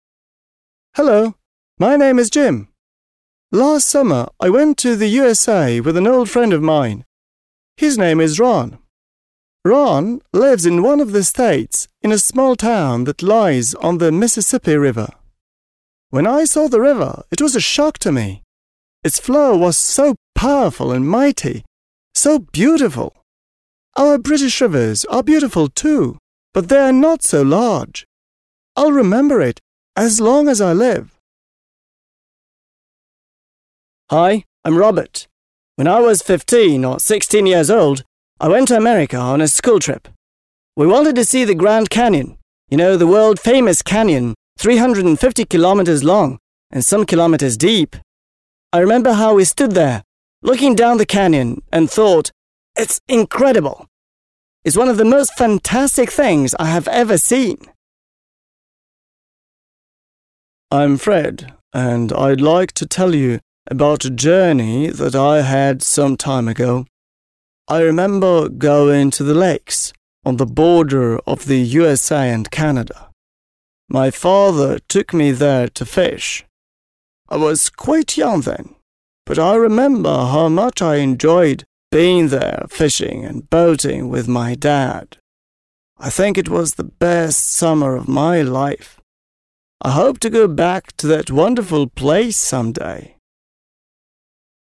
Для выполнения этого задания мы прослушали рассказы трех мальчиков и выделили ключевые слова, указывающие на географические названия.